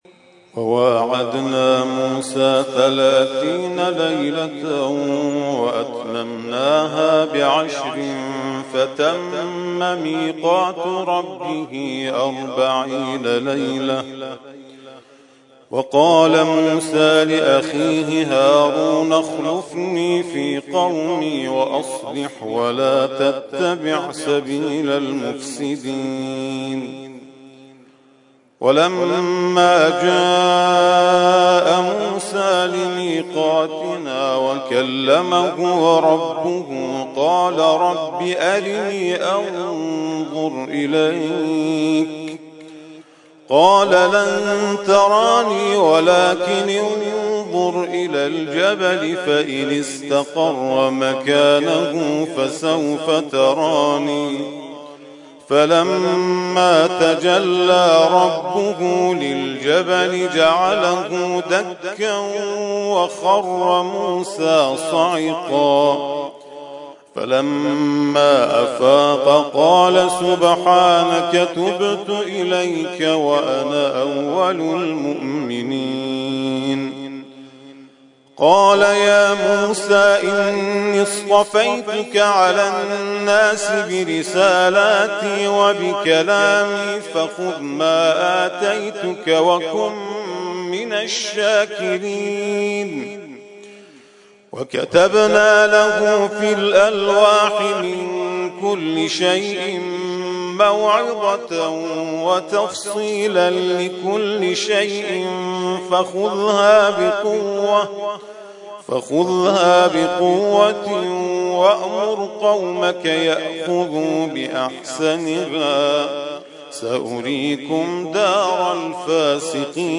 ترتیل خوانی جزء ۹ قرآن کریم در سال ۱۳۹۳